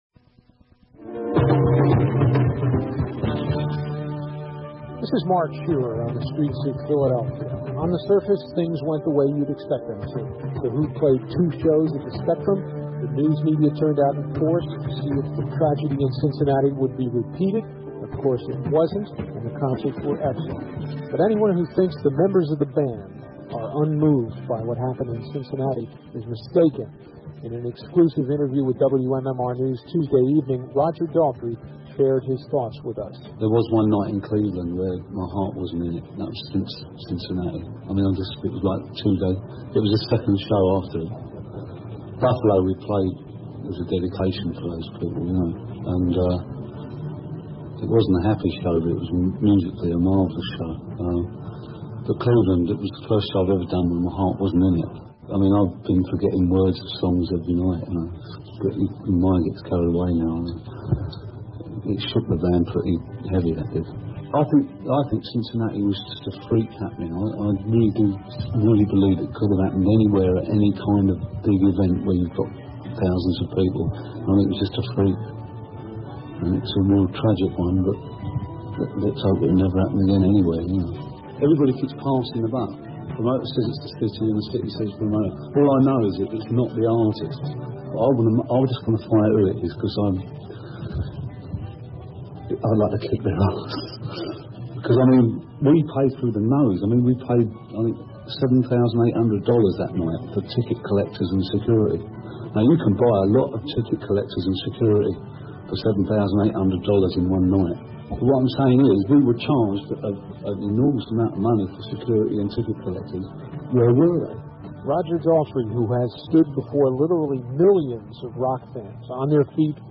The Philly Who concerts the following Monday and Tuesday went off without a hitch, and before the Tuesday show I interviewed a still shaken Roger Daltrey. It aired on a regular short feature called, “Streets of Philadelphia.”